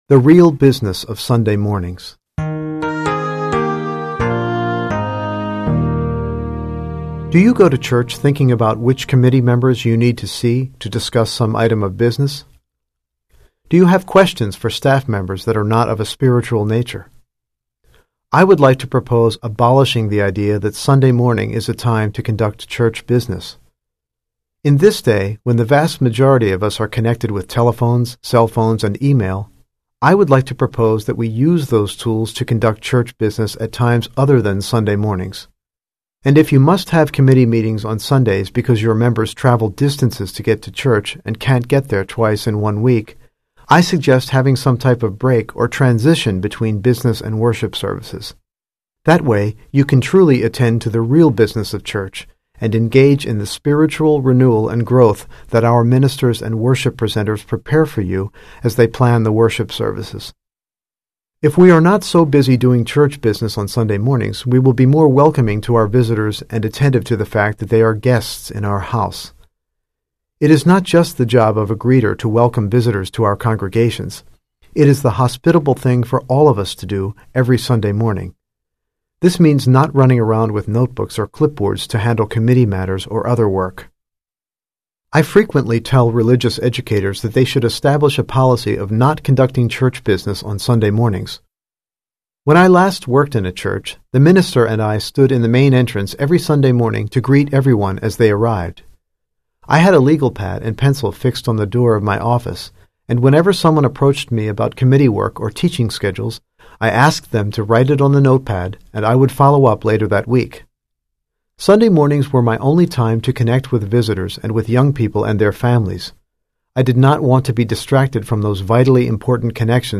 Audio Essay Series: Volume 1, Track 4 (MP3, 2:59 minutes)